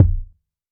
TC Kick 23.wav